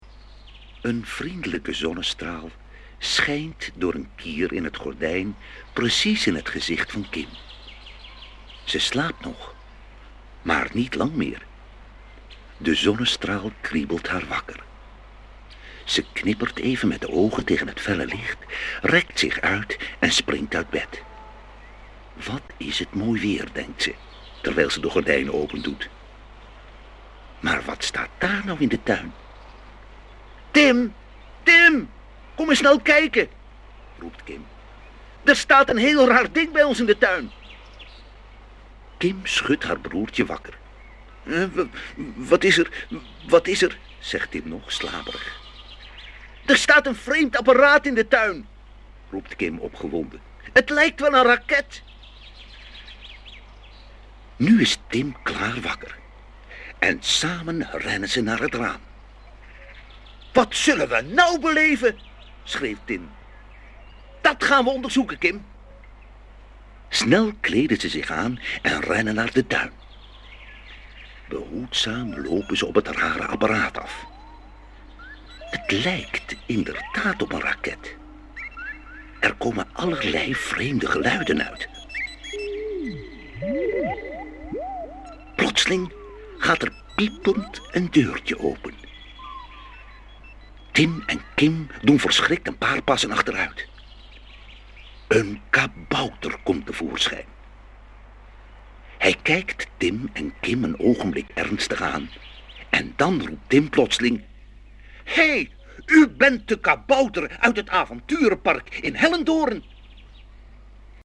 Liedjes en verhaal